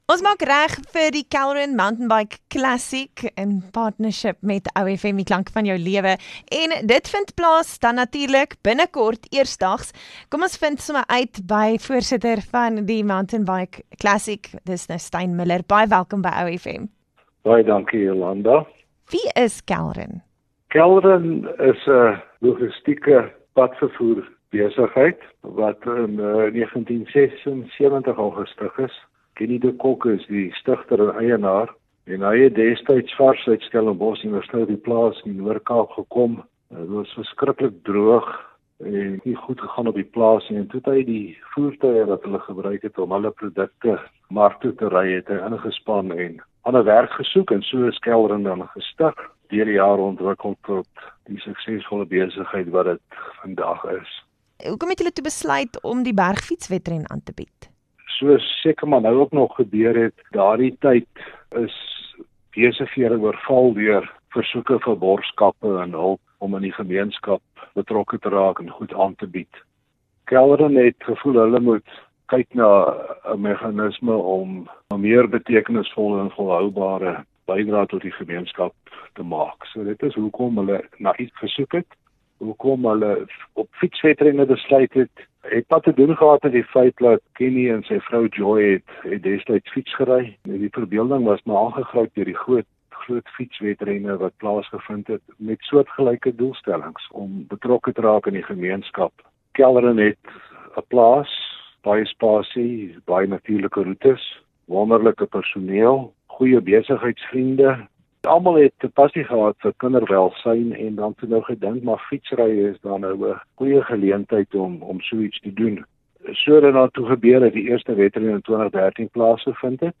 9 Oct Onderhoud 1: Die Kelrn Bergfietswedren